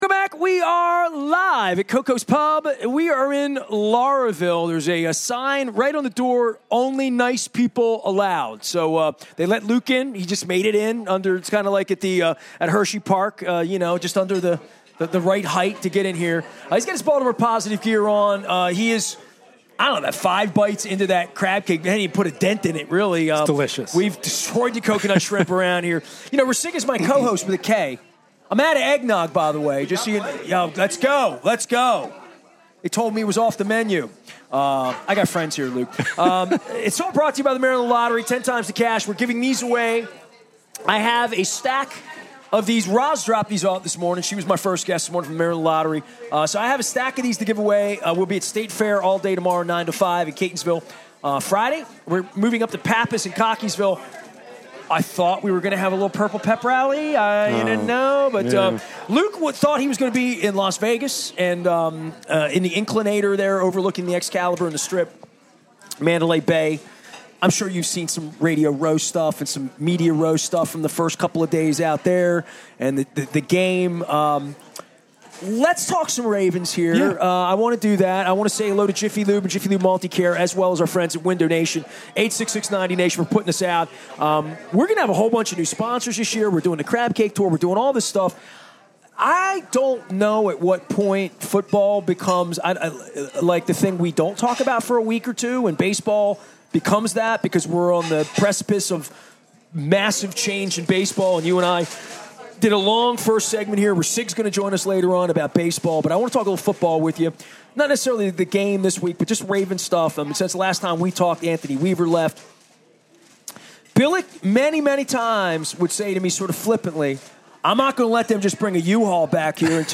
at Koco's on Crab Cake Row